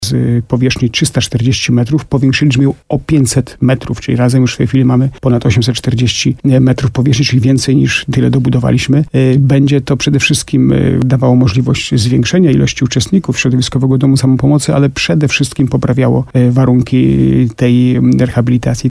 – To była bardzo solidna rozbudowa – mówi burmistrz Starego Sącza, Jacek Lelek.